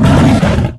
giant_attack_0.ogg